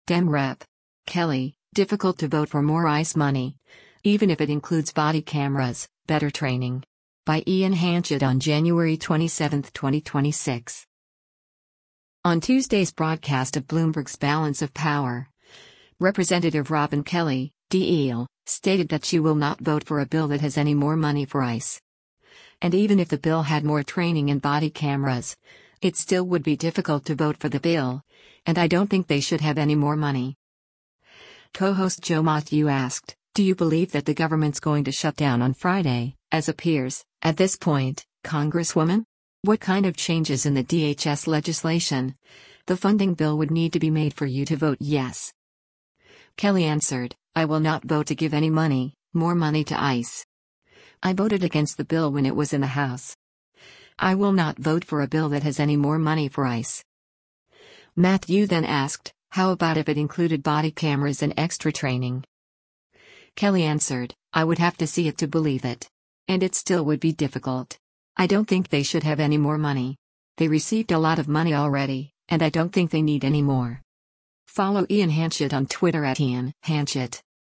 On Tuesday’s broadcast of Bloomberg’s “Balance of Power,” Rep. Robin Kelly (D-IL) stated that she “will not vote for a bill that has any more money for ICE.” And even if the bill had more training and body cameras, “it still would be difficult” to vote for the bill, and “I don’t think they should have any more money.”